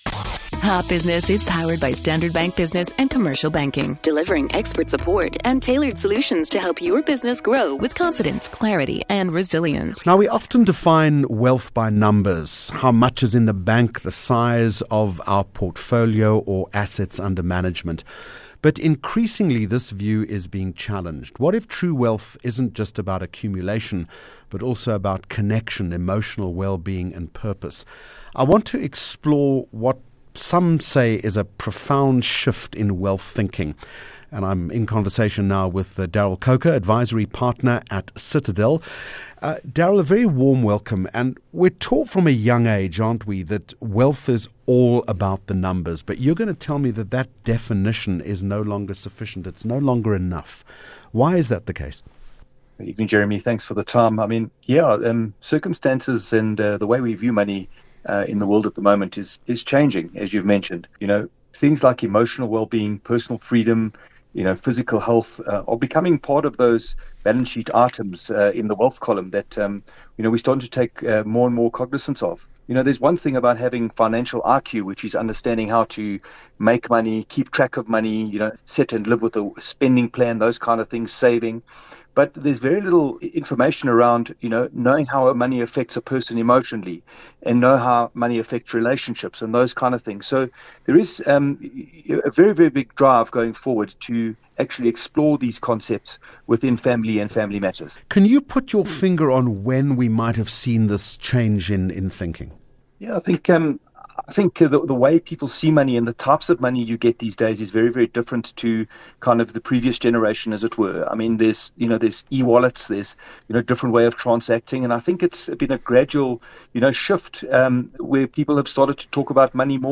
World Wide Business Brief: Topic: Exploring the emotional side of wealth Guest